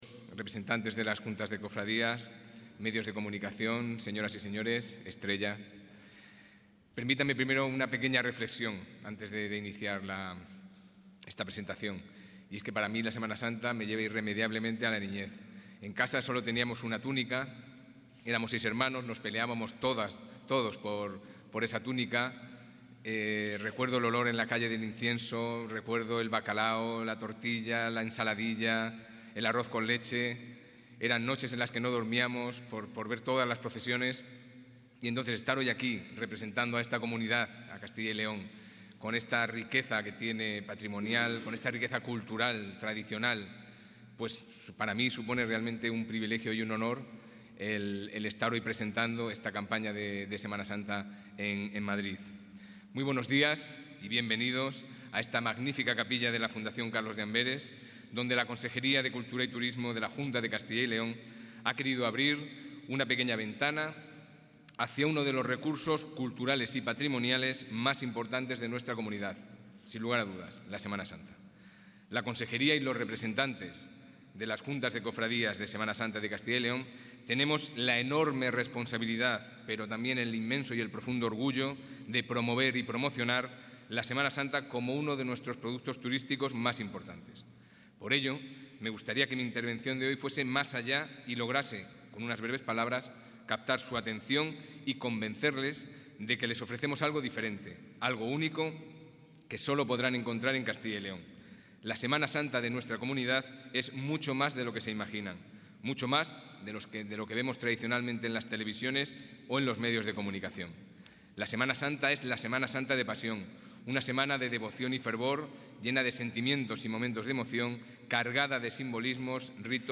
Intervención del consejero de Cultura y Turismo.